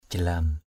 /ʥa-la:m/ (đg.) trườn = ramper. kamar jalam ruai kmR jl’ =r& trẻ con trườn bò = les enfants vont à quatre pattes.